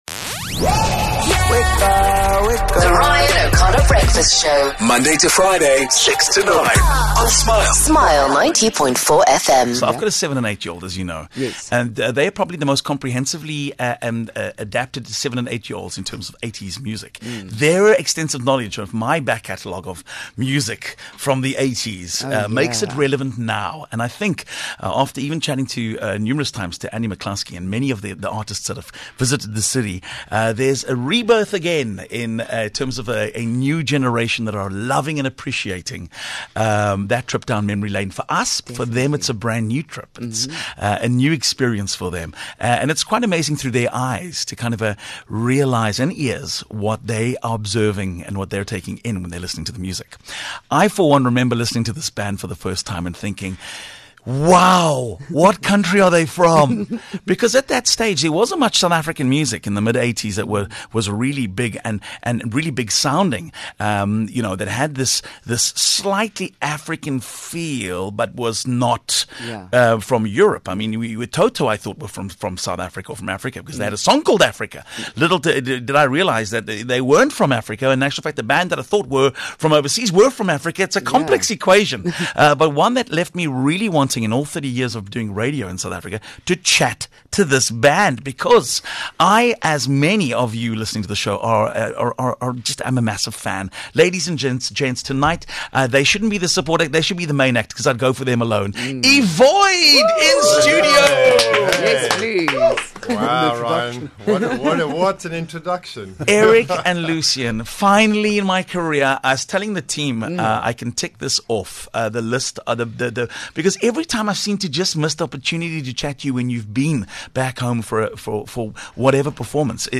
18 Apr éVoid live in studio